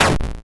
bigshot.wav